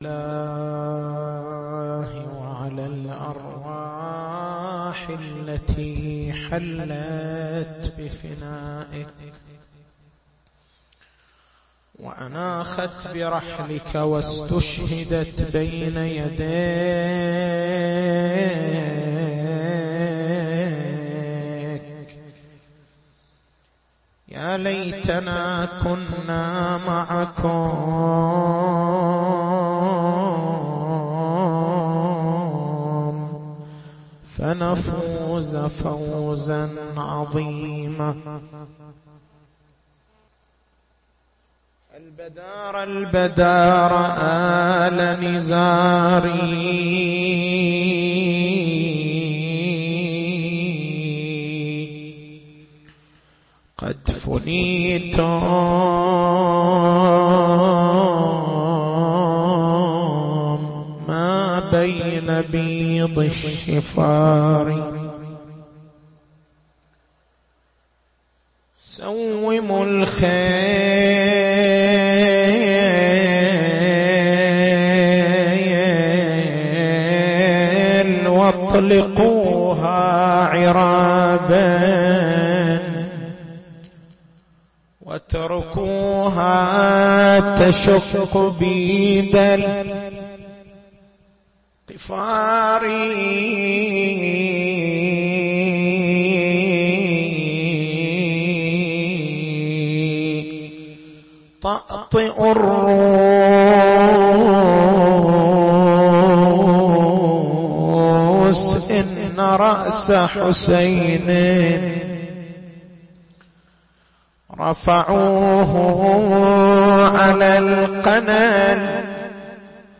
تاريخ المحاضرة: 26/09/1433 محور البحث: لماذا يبدأ الأذان والإقامة بالتكبير ويختتمان بالتهليل؟